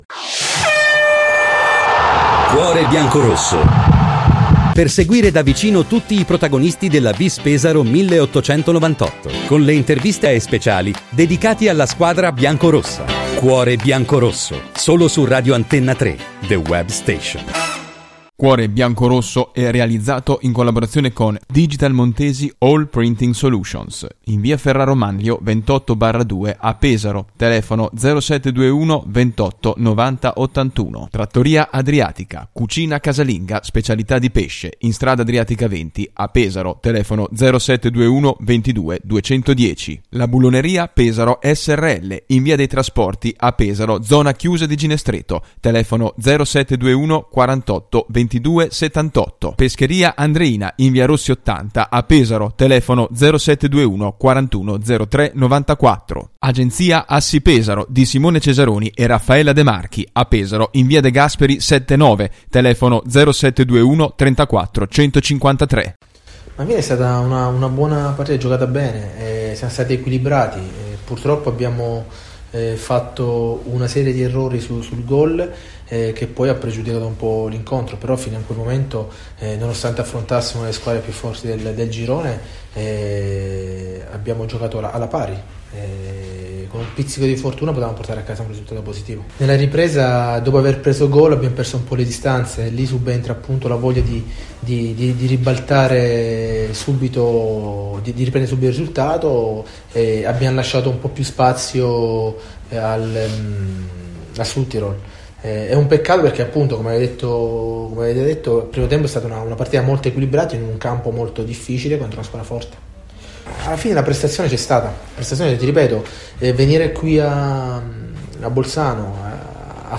Le interviste del dopo partita a